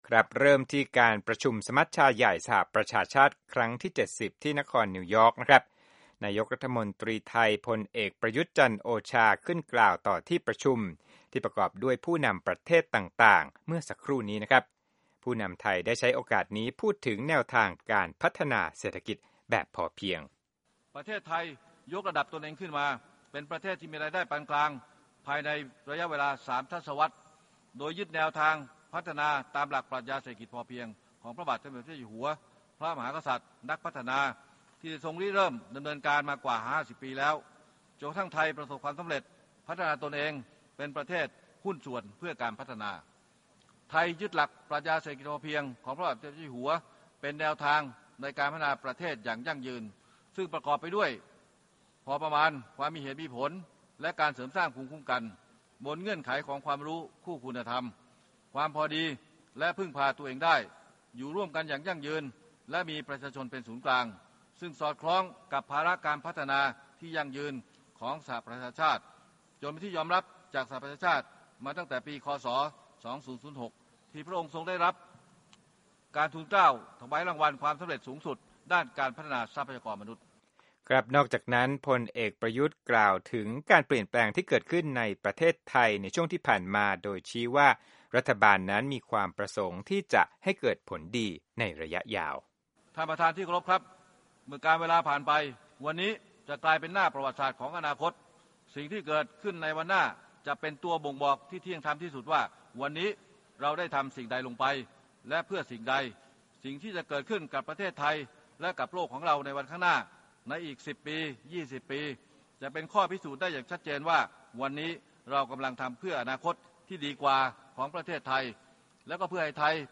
Thai PM UN Speech